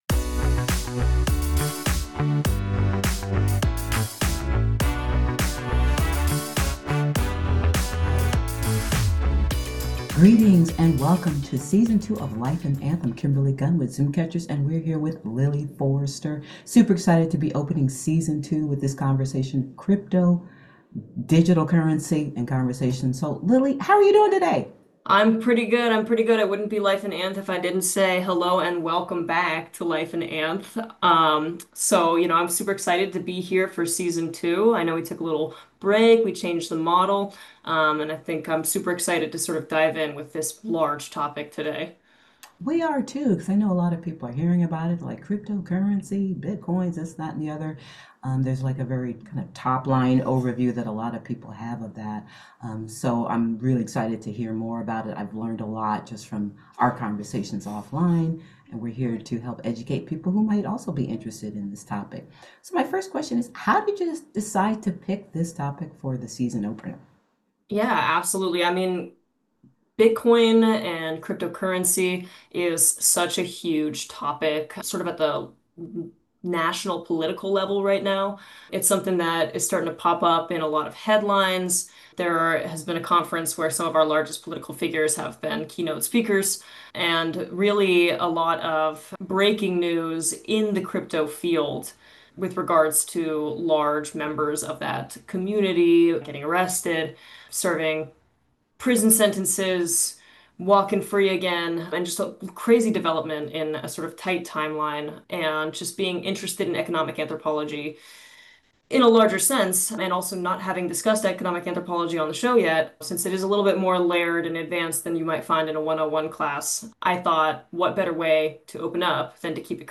Tune in for a thought-provoking conversation that goes beyond the headlines to examine the human side of crypto and digital culture!